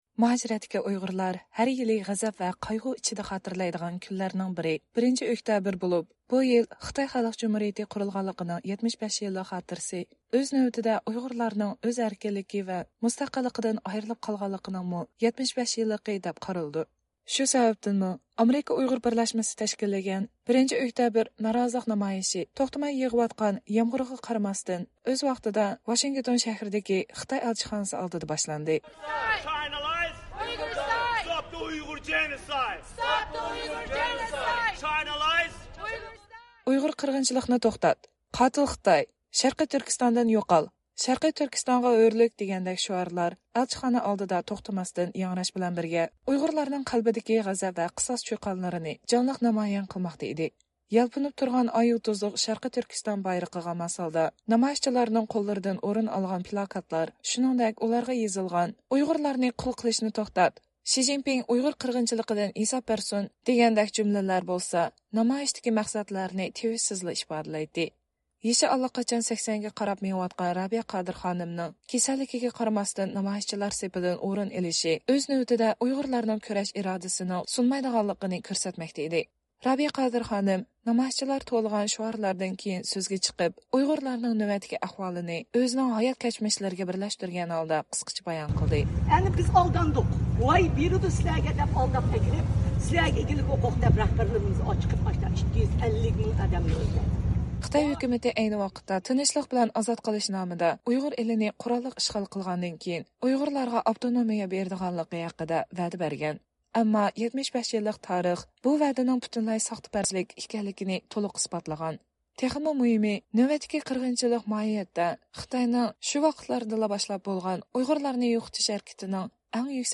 ئامېرىكا ئۇيغۇر بىرلەشمىسى تەشكىللىگەن «1-ئۆكتەبىر نارازىلىق نامايىشى» نىڭ كۆرۈنۈشى. 2024-يىلى 1-ئۆكتەبىر، ۋاشىنگتون
نامايىش توختىماي يېغىۋاتقان يامغۇرغا قارىماستىن ئەنە شۇ يوسۇندا داۋام قىلىپ، جاراڭلىق شوئار سادالىرى بىلەن ئاياغلاشتى.